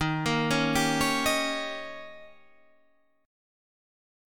Listen to D#7 strummed